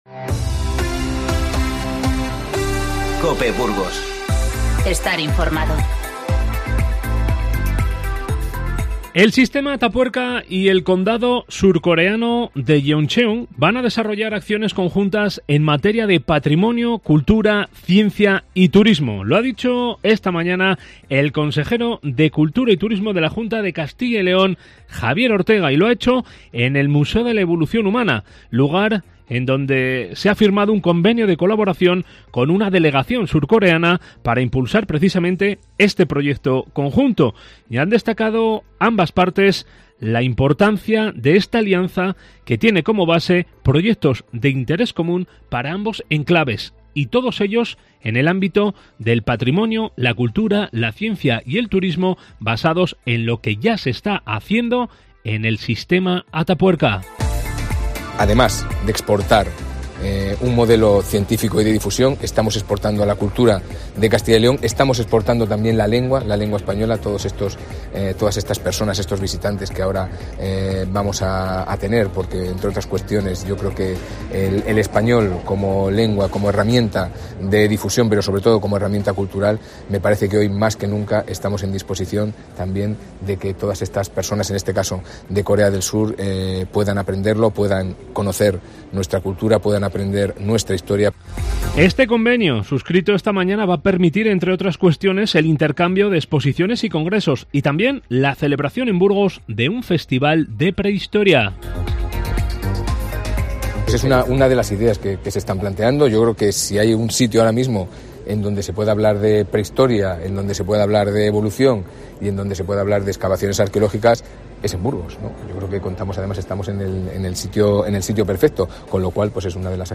Informativo 03-02-20